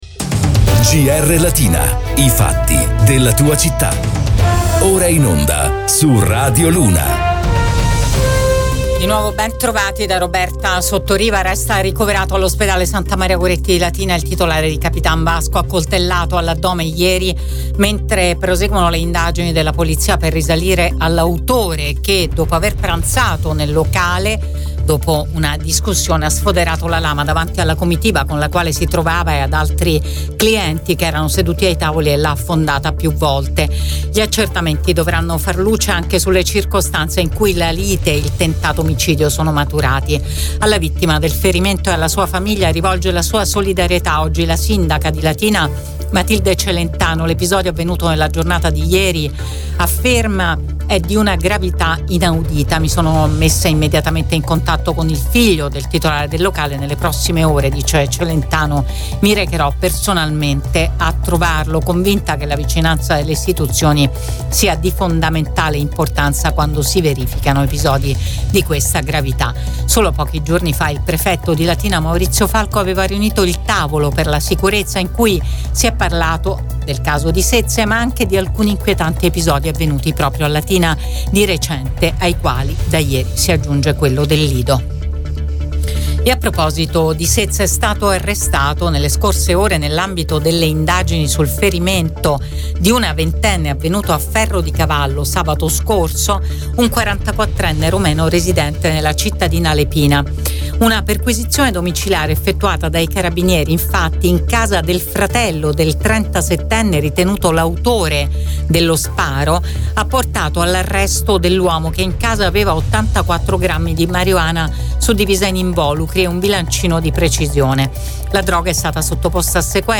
LATINA – Qui puoi ascoltare il podcast di GR Latina in onda su Radio Immagine, Radio Latina e Radio Luna